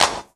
VEC3 Claps 005.wav